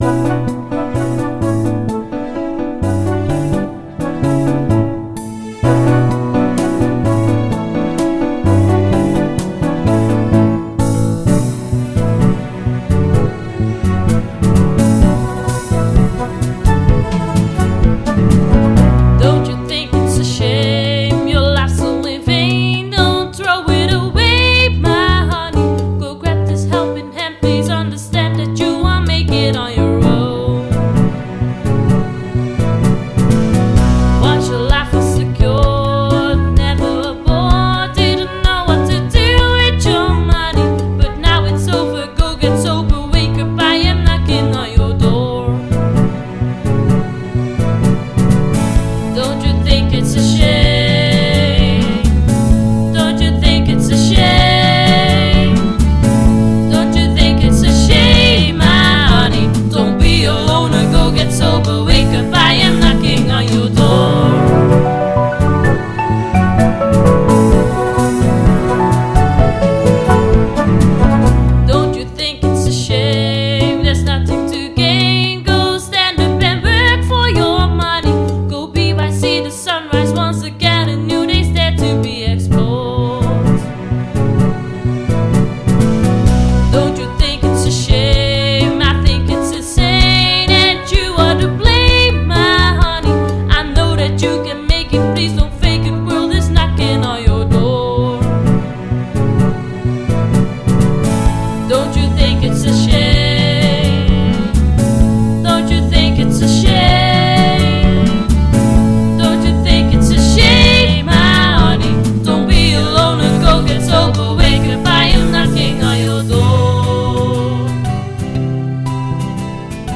Engelstalig, modern-populair)